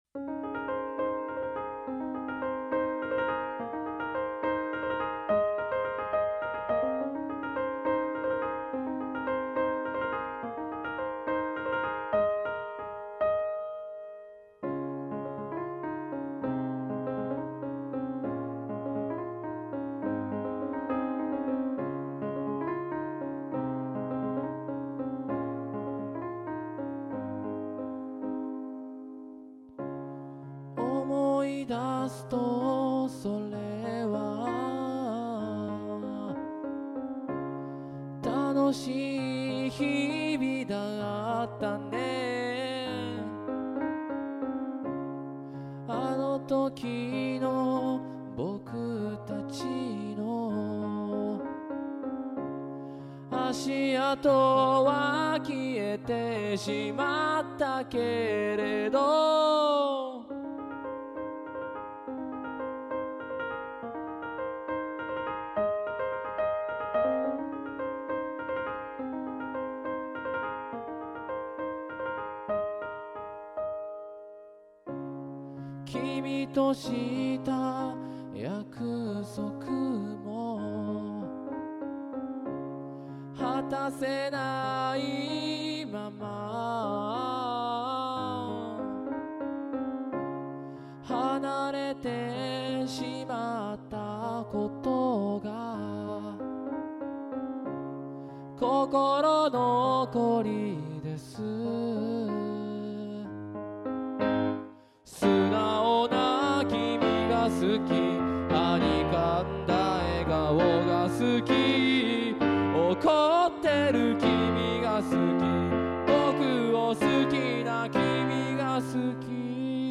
Keyボードの弾き語りで歌いあげます。